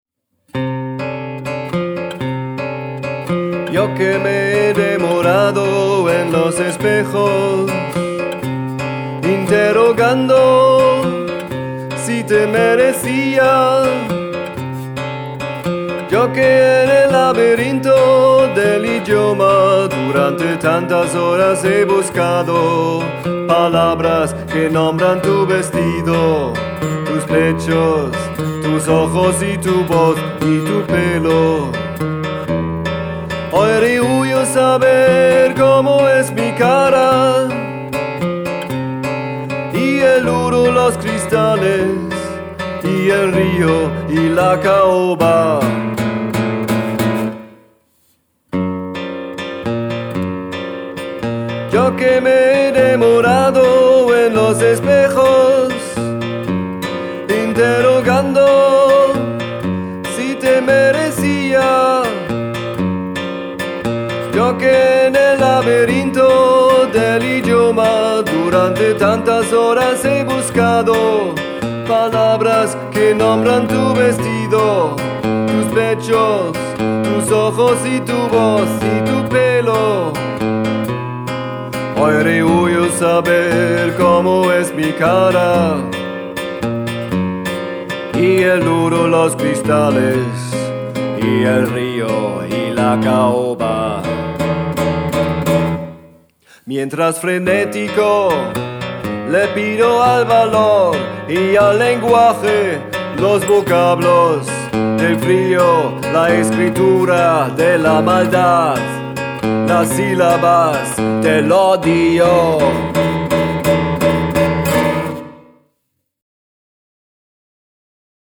Guitarra española